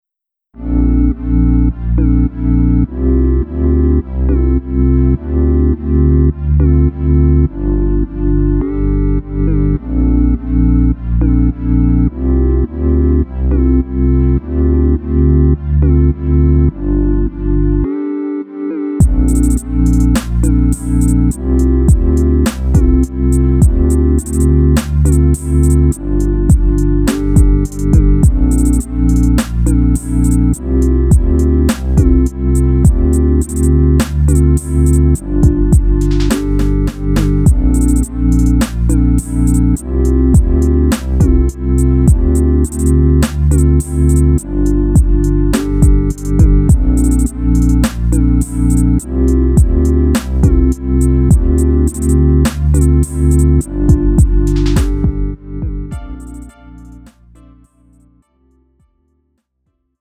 음정 원키 2:31
장르 가요 구분 Lite MR